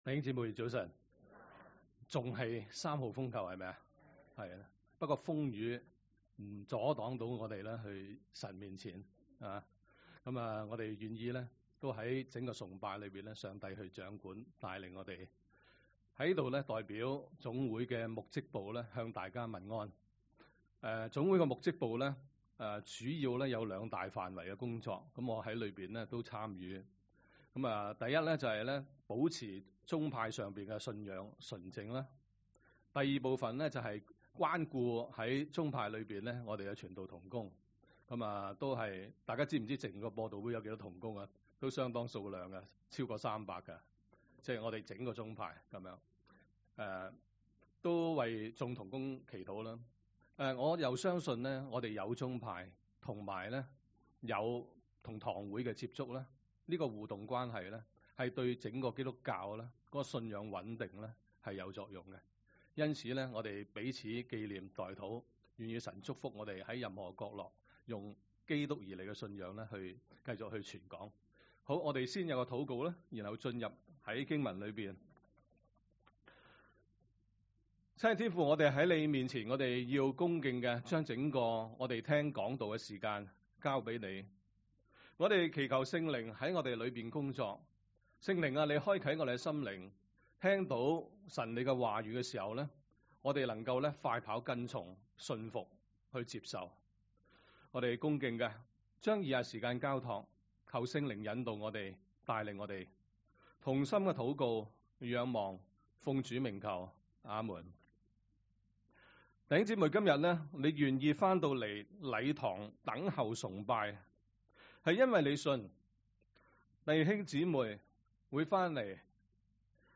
講道 ： 等候主再來